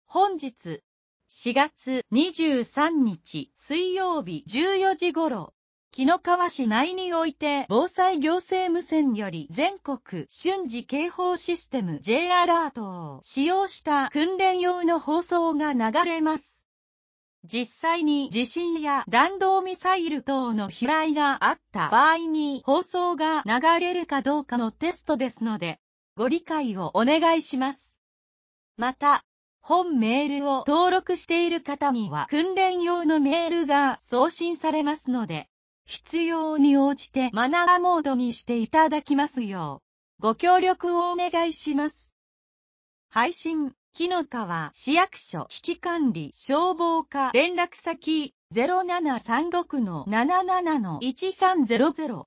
本日、４月２３日（水）１４時頃、紀の川市内において防災行政無線より全国瞬時警報システム（Ｊアラート）を使用した訓練用の放送が流れます。実際に地震や弾道ミサイル等の飛来があった場合に放送が流れるかどうかのテストですので、ご理解をお願いします。